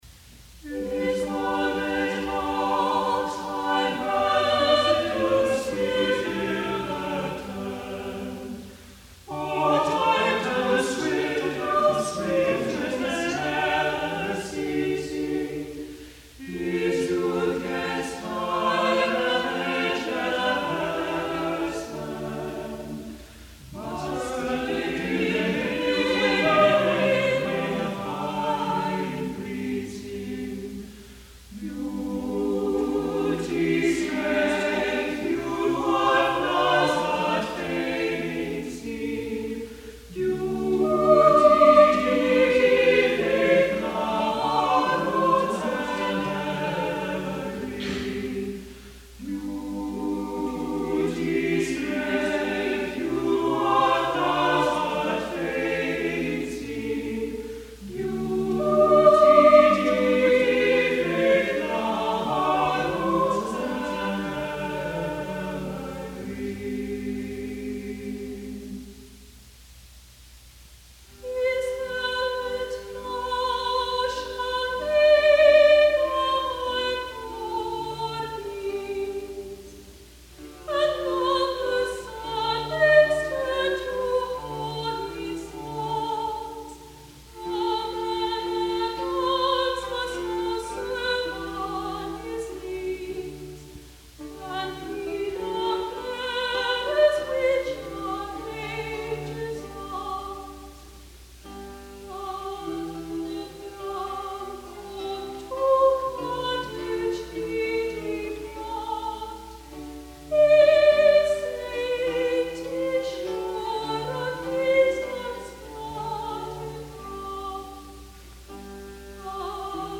Also from Dowland ‘A First Book of Songs’, “His golden locks” is a strophic lute-song written on the occasion of Sir Henry Lee’s retirement from court in 1590.
soprano
Lute